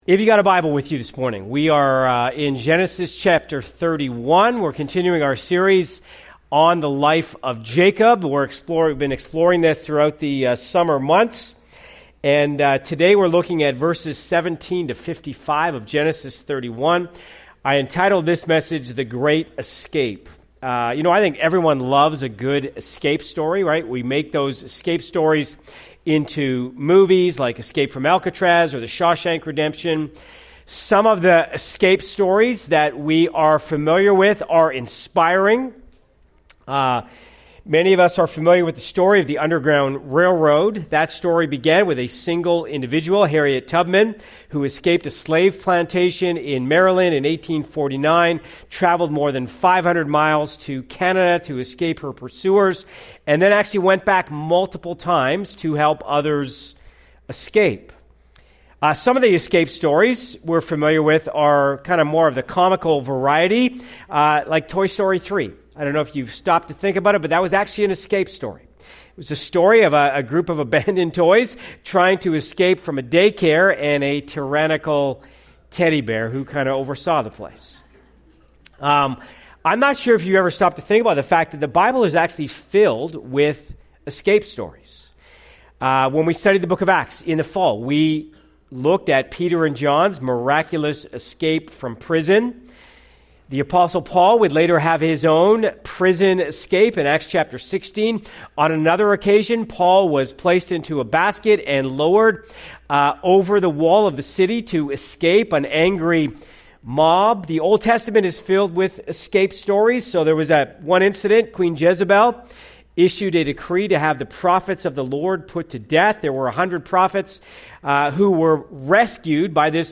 Part of our series, “ Straight Lines with Crooked Sticks ,” following the life of Jacob in the book of Genesis. CLICK HERE for other sermons from this series.